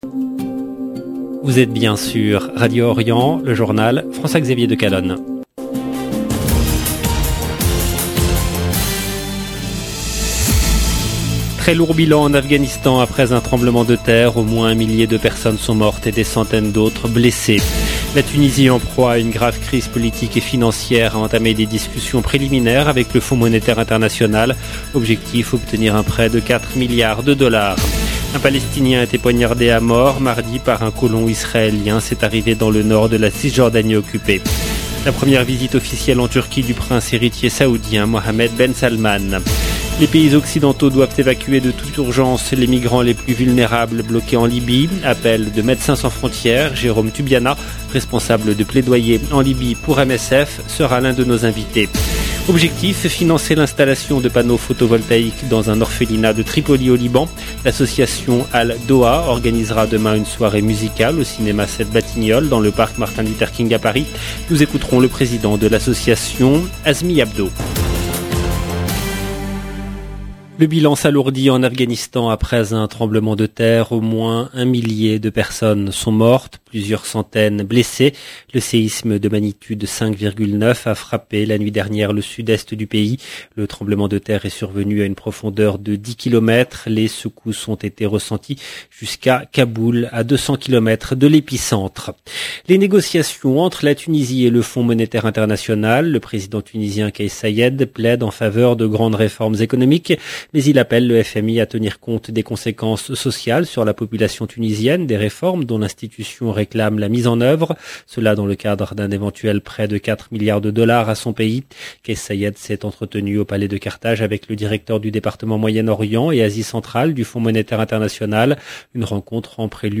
LE JOURNAL EN LANGUE FRANCAISE DU SOIR DU 22/06/22